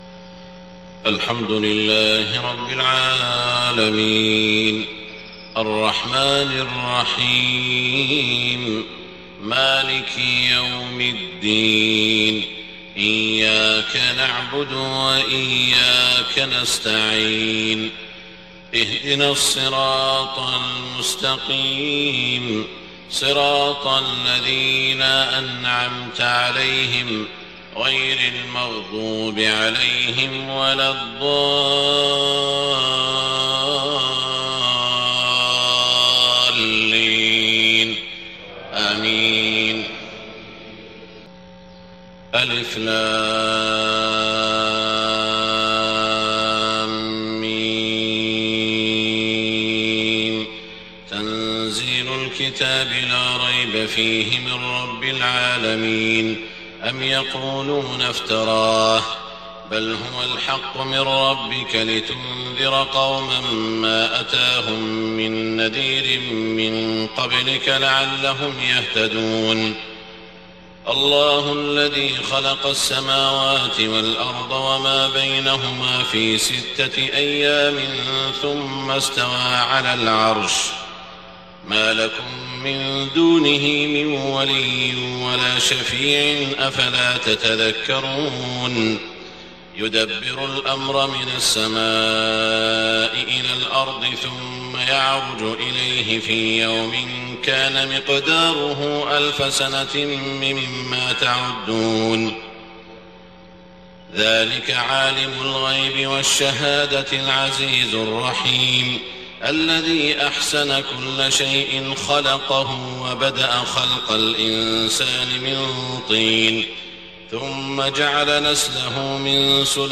صلاة الفجر 9 ربيع الأول 1430هـ سورتي السجدة و الإنسان > 1430 🕋 > الفروض - تلاوات الحرمين